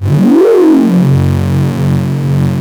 OSCAR 10 F#1.wav